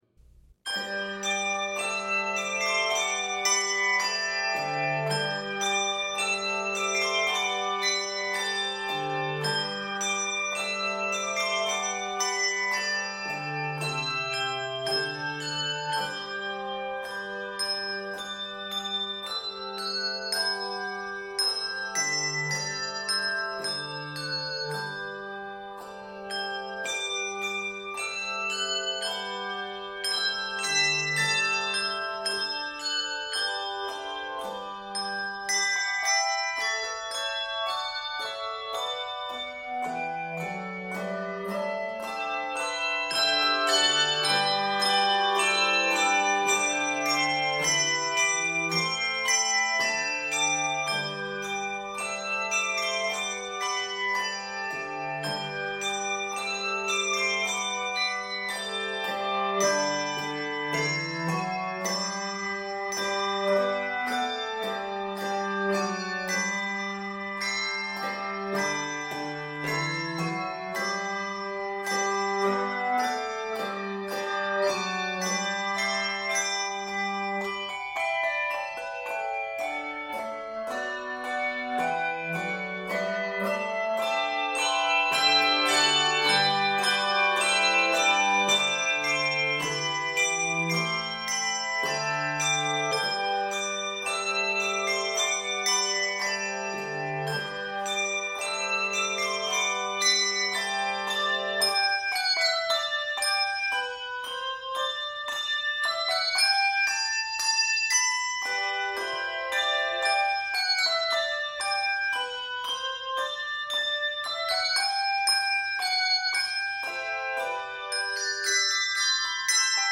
Keys of G Major and Bb Major.
Octaves: 3-5